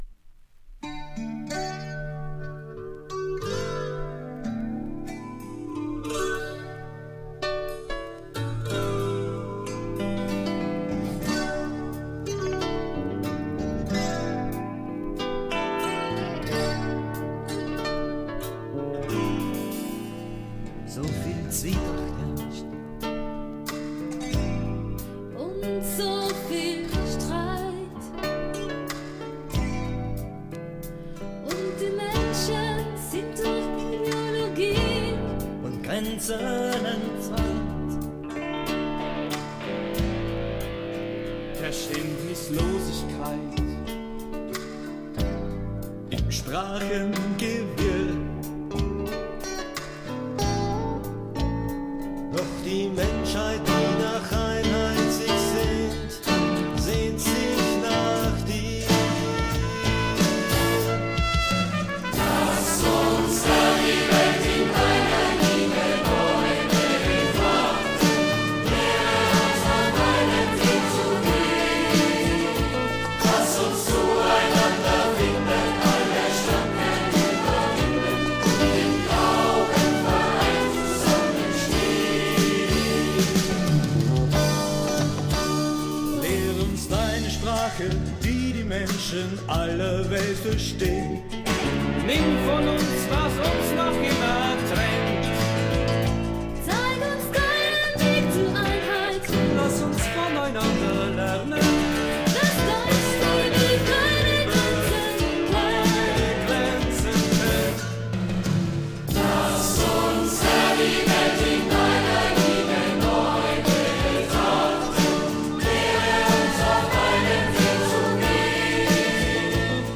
Lieder mit Chorsätzen